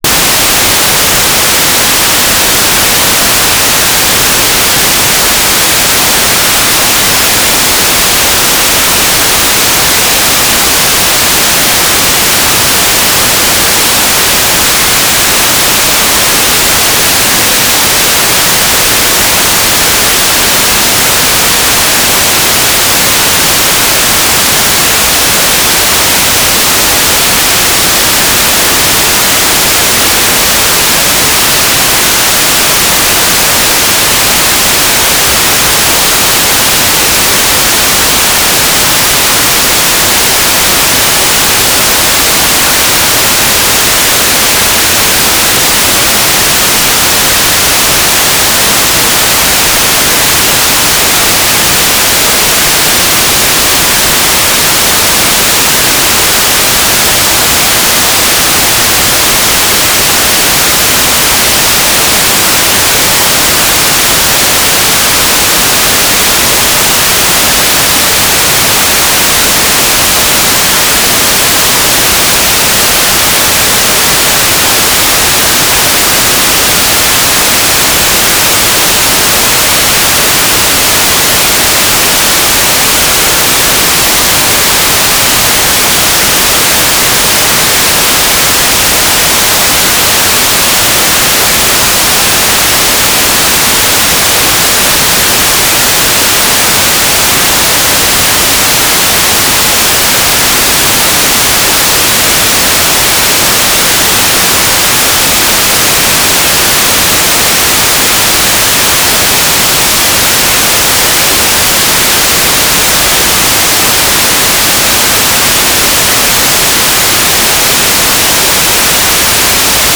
"waterfall_status": "without-signal",
"transmitter_description": "Downlink",
"transmitter_mode": "FM",